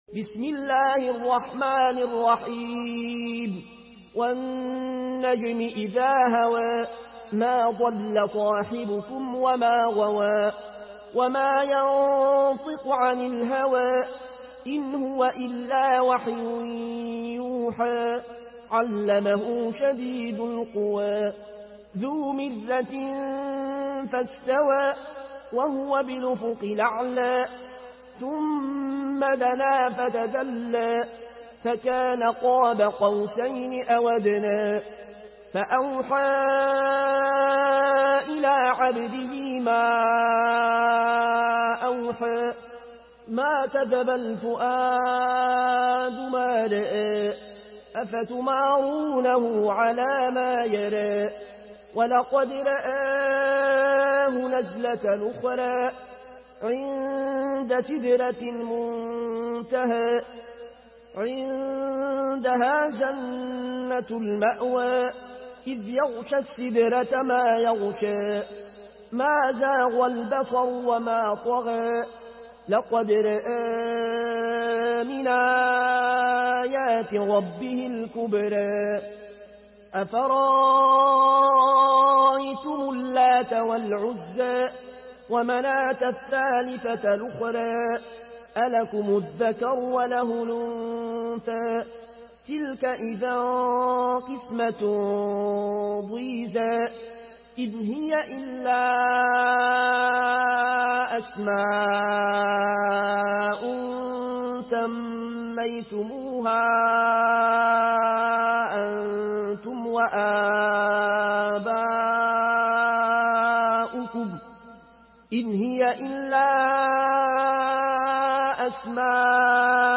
مرتل ورش عن نافع From الأزرق way